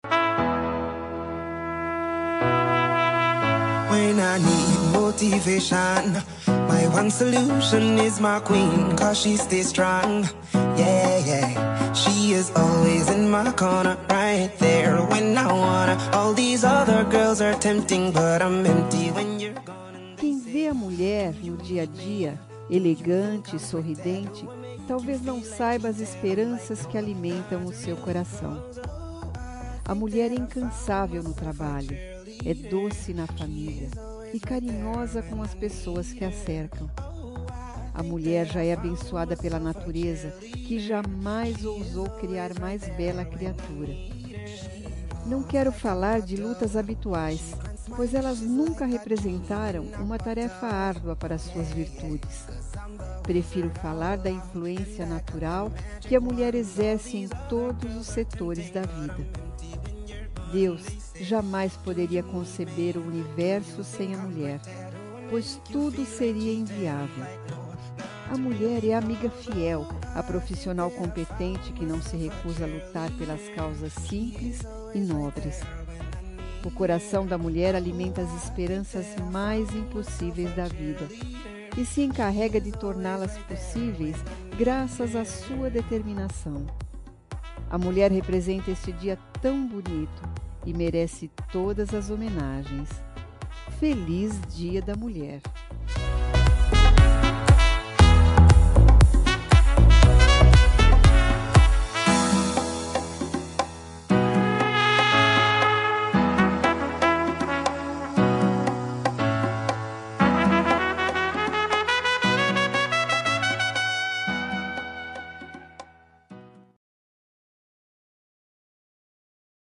Dia das Mulheres Neutra – Voz Feminina – Cód: 5295